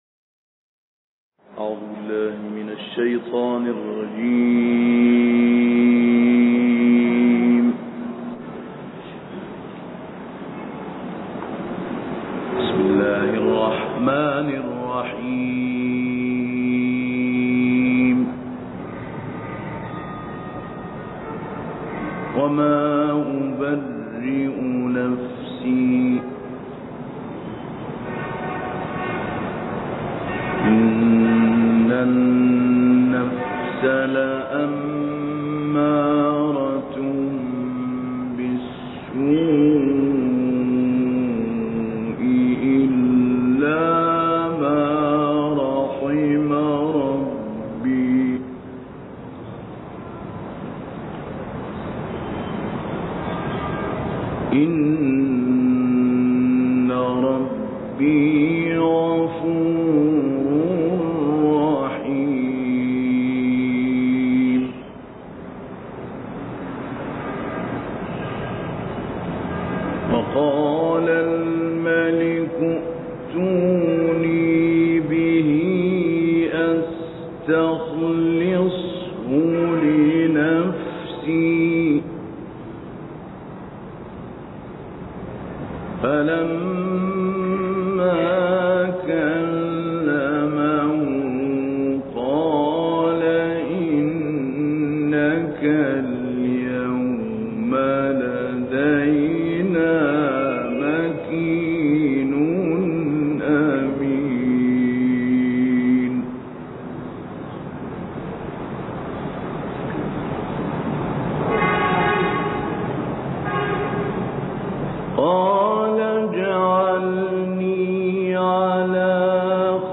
دانلود قرائت سوره یوسف آیات 53 تا 76 - استاد علی البنا